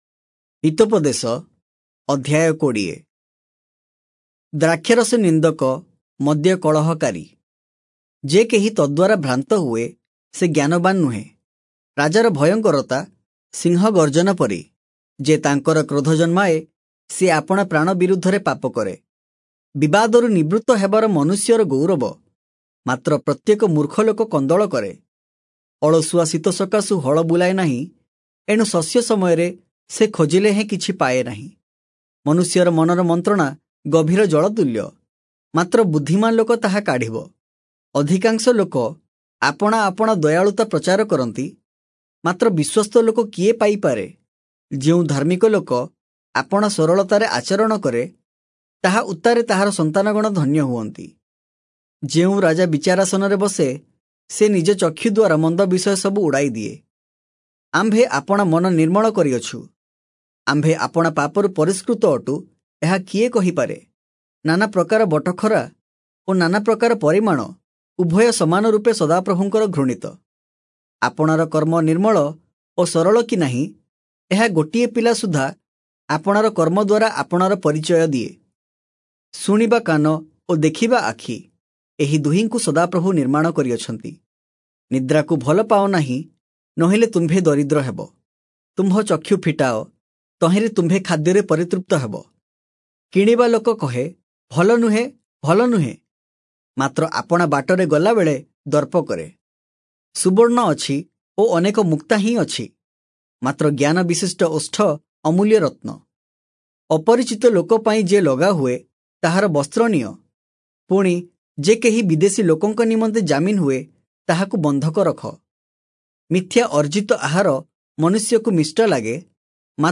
Oriya Audio Bible - Proverbs 12 in Irvor bible version